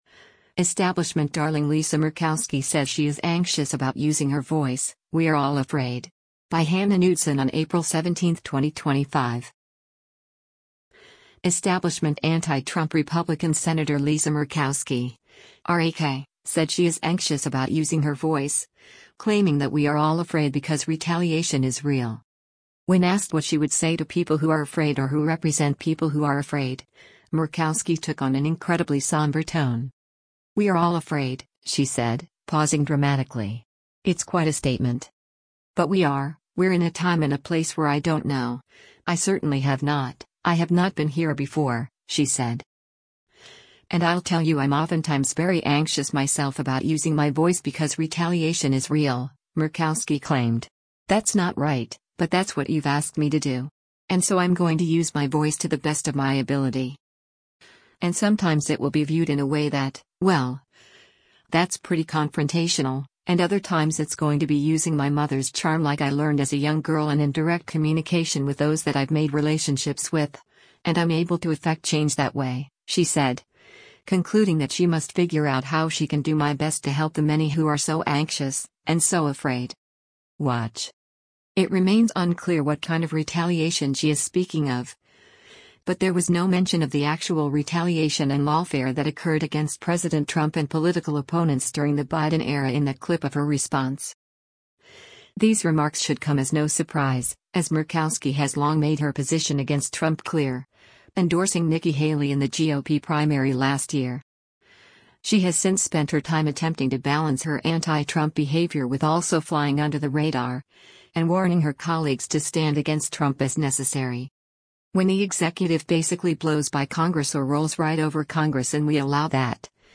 When asked what she would say to people who are afraid or who represent people who are afraid, Murkowski took on an incredibly somber tone.
“We are all afraid,” she said, pausing dramatically.